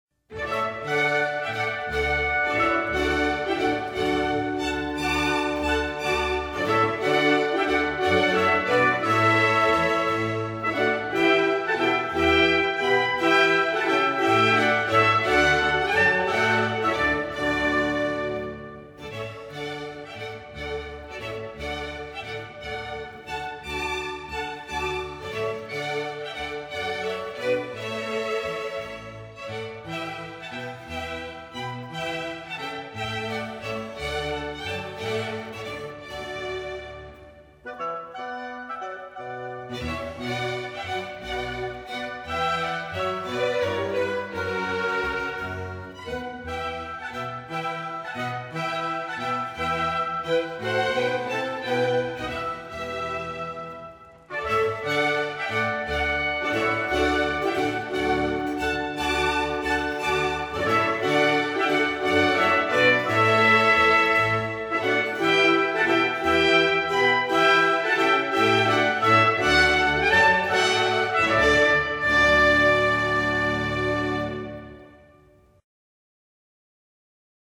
缓慢的
节拍稳定流畅，具有类似声乐的优美线条。